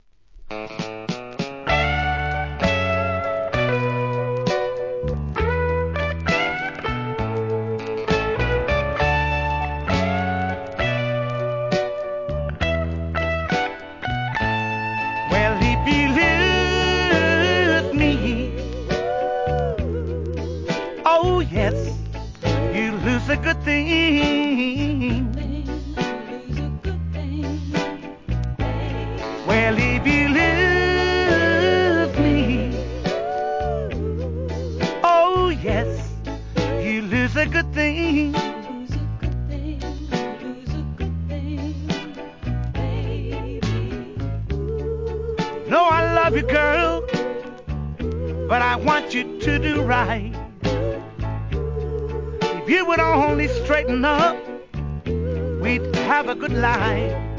¥ 550 税込 関連カテゴリ SOUL/FUNK/etc...
メランコリック・ソウル